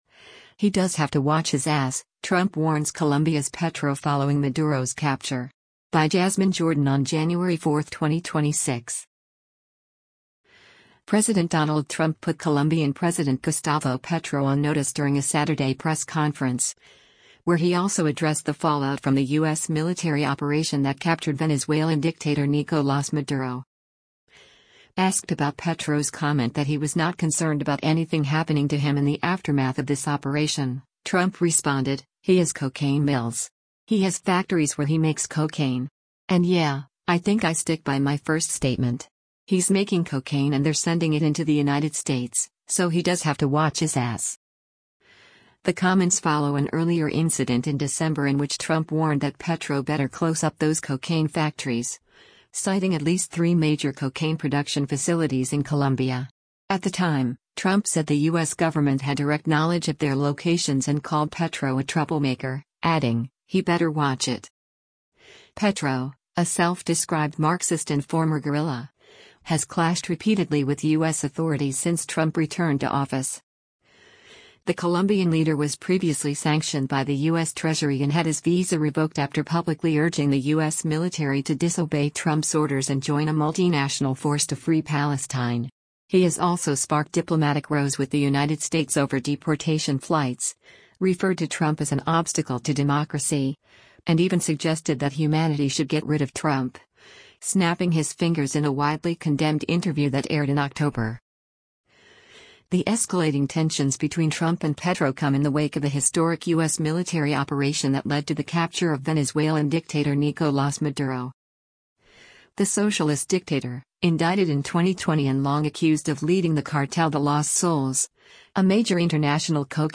President Donald Trump put Colombian President Gustavo Petro on notice during a Saturday press conference, where he also addressed the fallout from the U.S. military operation that captured Venezuelan dictator Nicolás Maduro.